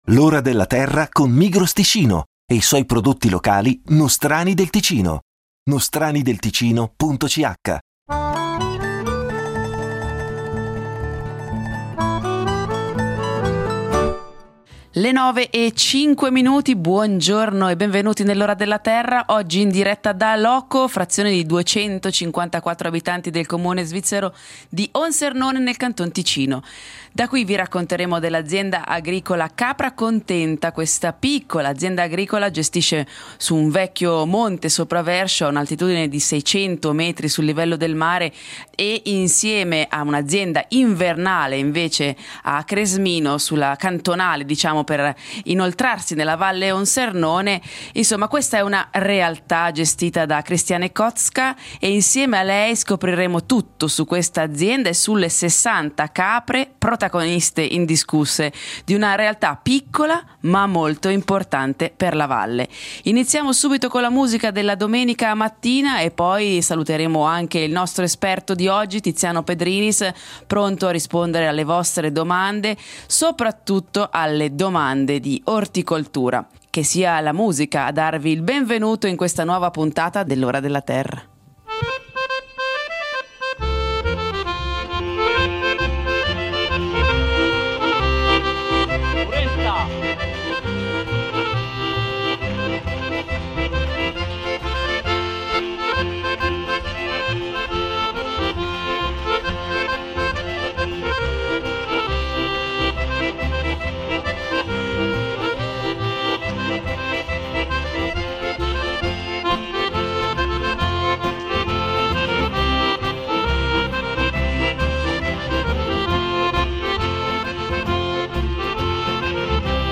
In diretta da Loco, vi racconteremo dell’azienda agricola “ capra contenta ”. Questa piccola azienda caprina gestisce un vecchio monte sopra Verscio ad un’altitudine di 600 m/sm ed un’azienda invernale a Cresmino sulla cantonale per la Valle Onsernone, a 2 chilometri da Cavigliano.